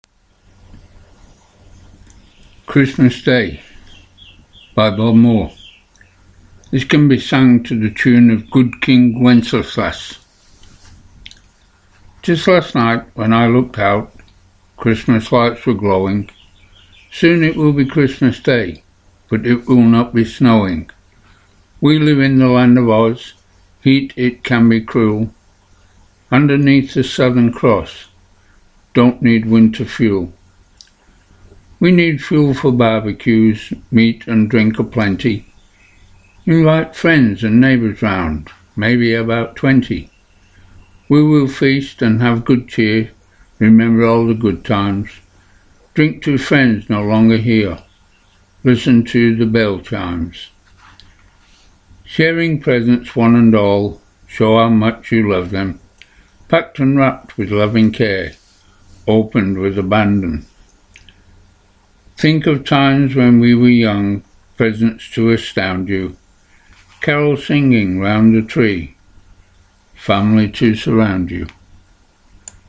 Can be sung to the tune of Good King Wenceslas